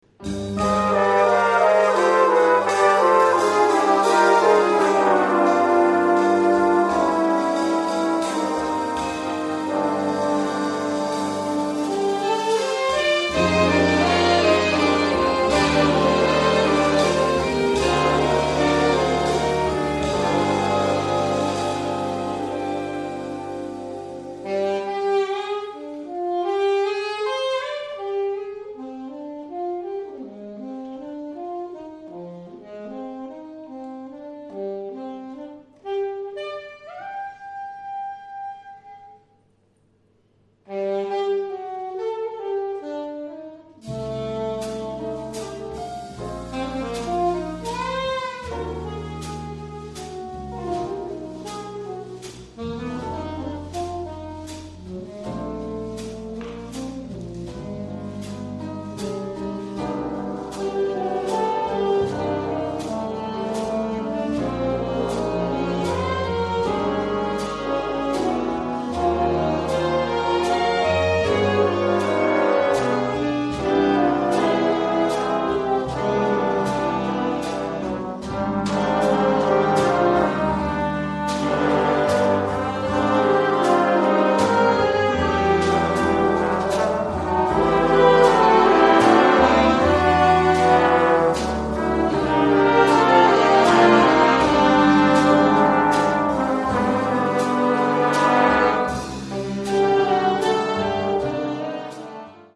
Baritone plays flute. A feature for 2nd Alto.